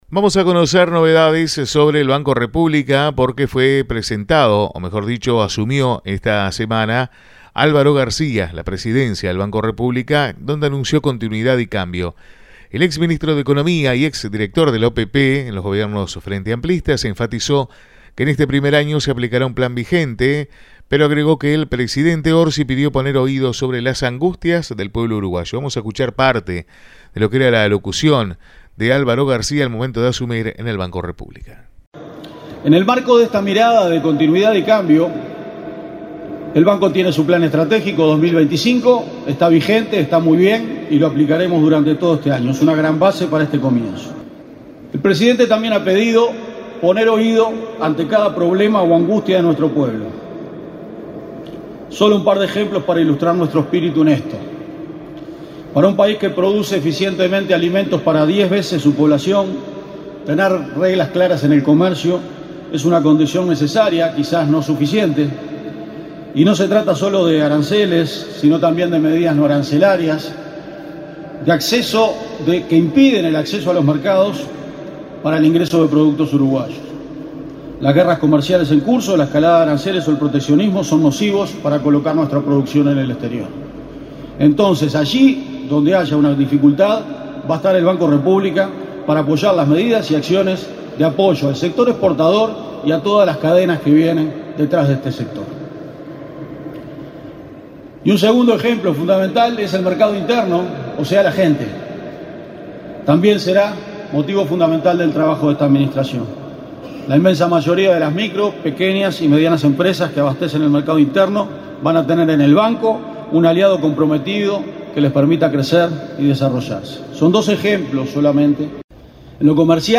Álvaro García presidente del BROU en dialogo con Mediodia Rural